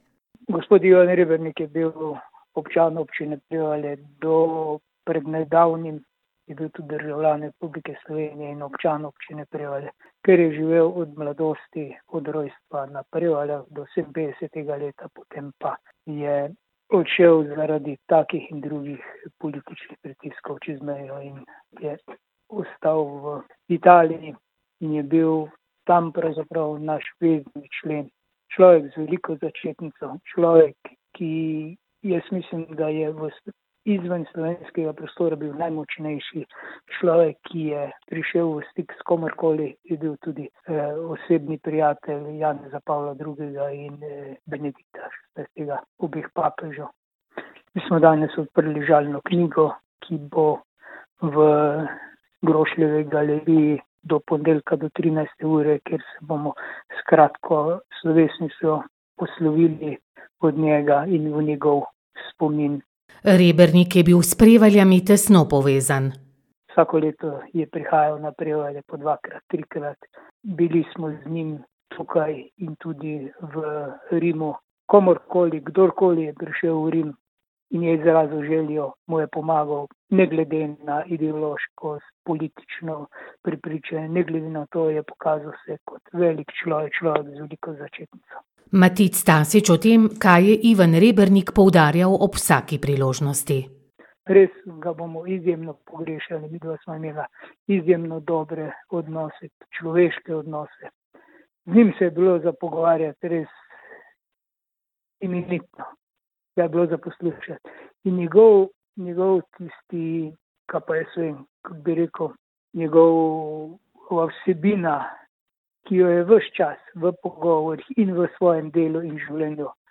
Po smrti nekdanjega veleposlanika pri Svetem sedežu, Ivana Rebernika, se bodo od svojega rojaka in častnega občana poslovili tudi na Prevaljah. Kako bodo potekale spominske slovesnosti, je povedal župan Matic Tasič: